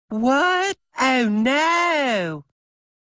This high-quality sound effect is part of our extensive collection of free, unblocked sound buttons that work on all devices - from smartphones to desktop computers.